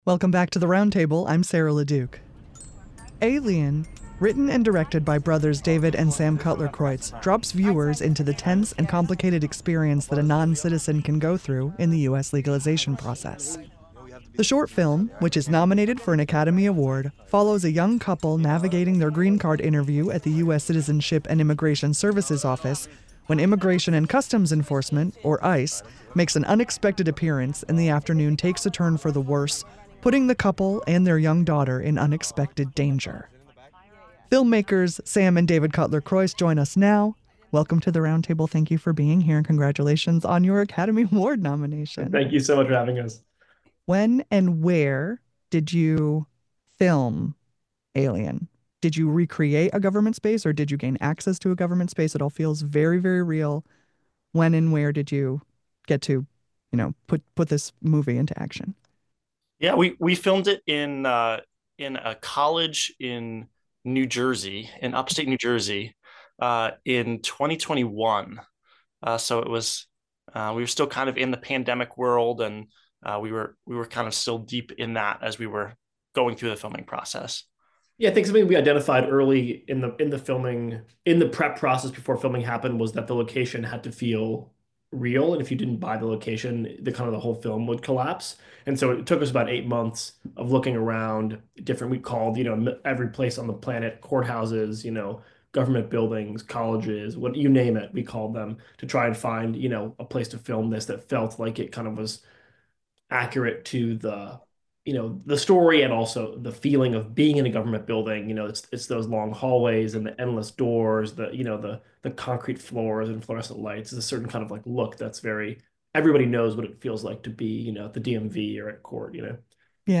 WAMC's The Roundtable is an award-winning, nationally recognized eclectic talk program.